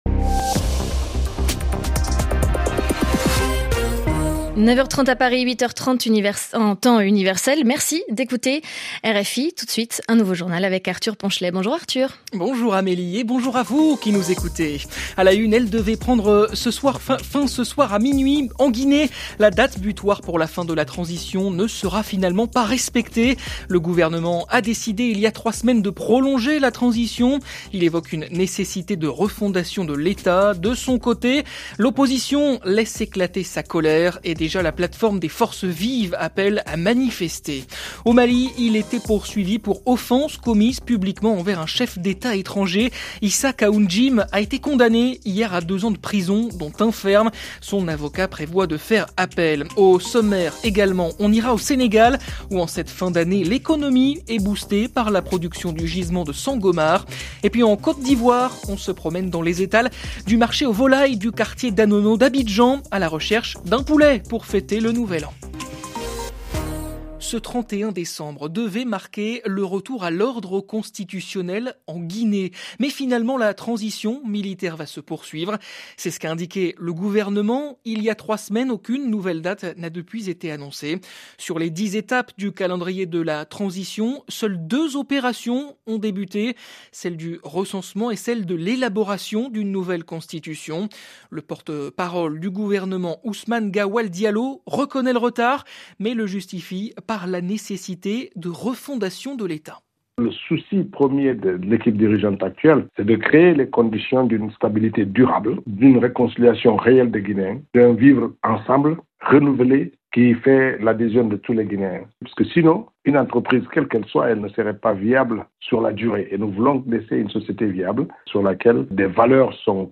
Derniers journaux et sessions d’informations